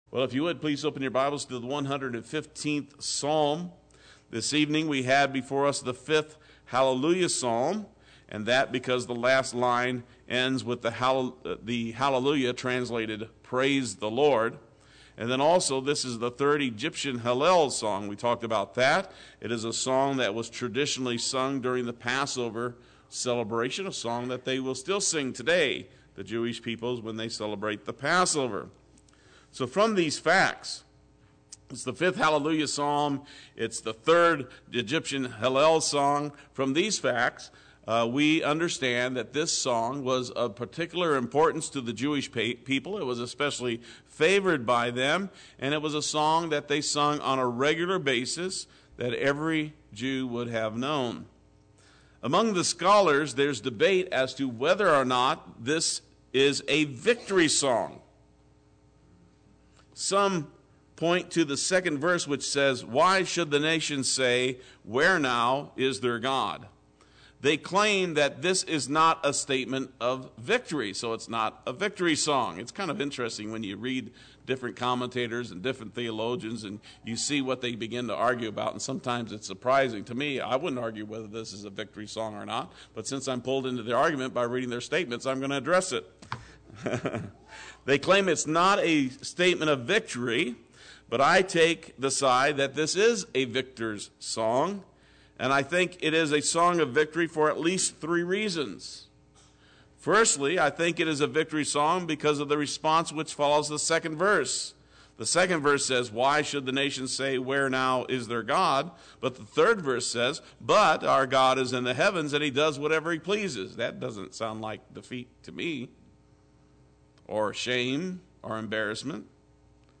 Play Sermon Get HCF Teaching Automatically.